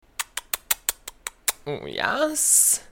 Uuuhhh YAAASSS Effect button Uuuhhh YAAASSS Effect Sound Uuuhhh YAAASSS Efffect Download Download Download for iphone